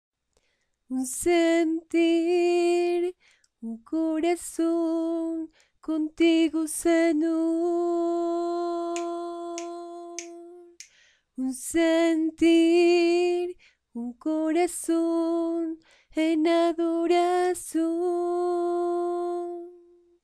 3a Voz Precoro Mujer